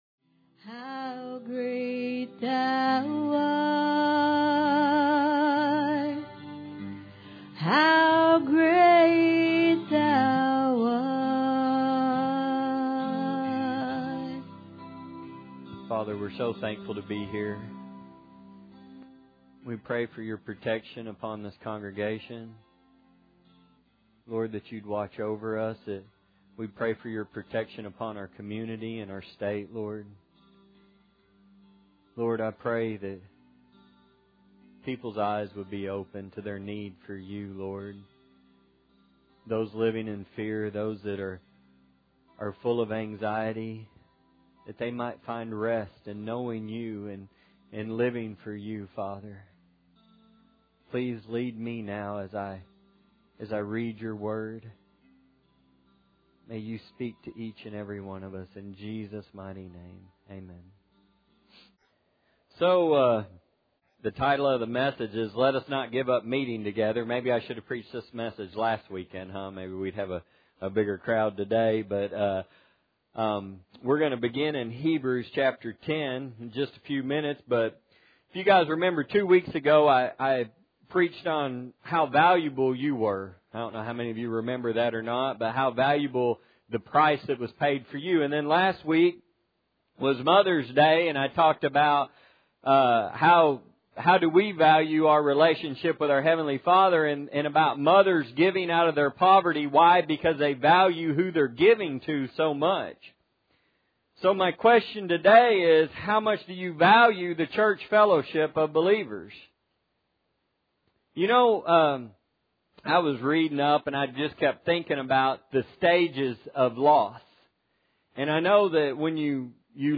Isaiah 43:14-21 Service Type: Sunday Morning Audio Version Below